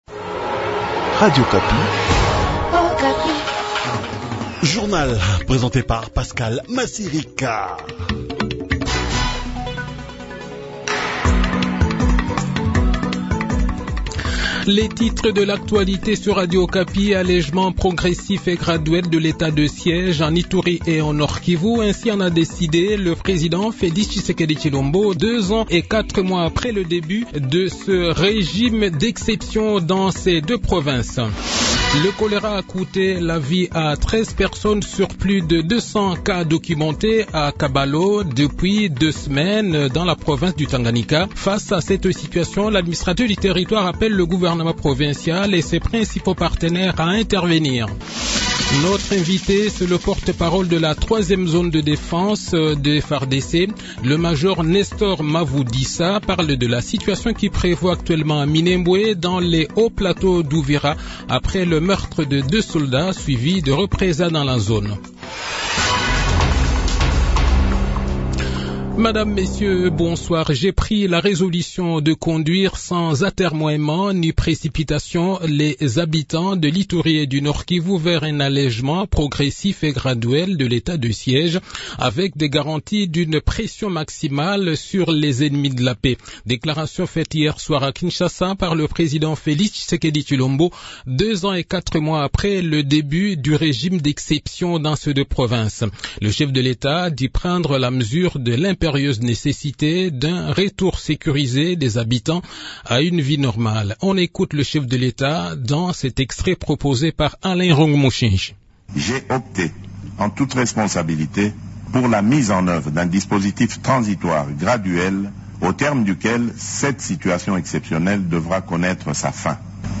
Le journal de 18 h, 13 octobre 2023